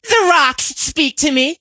carl_start_vo_03.ogg